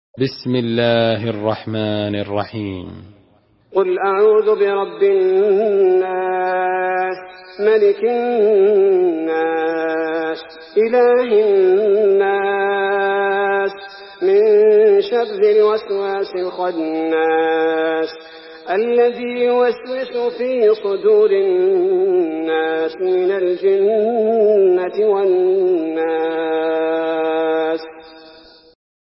Surah Nas MP3 in the Voice of Abdul bari al thubaity in Hafs Narration
Murattal Hafs An Asim